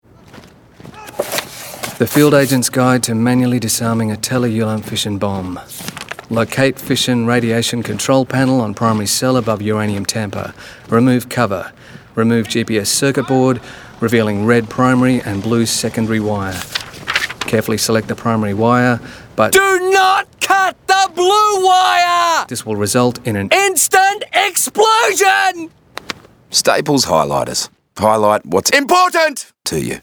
Staples_Radio_BOMB_FinalMix_APR17.mp3